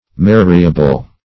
Marriable \Mar"ri*a*ble\, a.
marriable.mp3